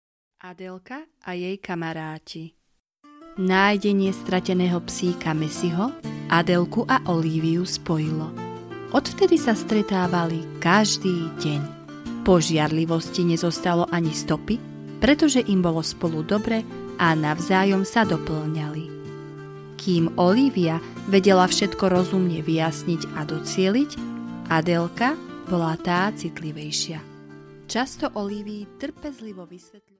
Adelka a jej kamaráti audiokniha
Ukázka z knihy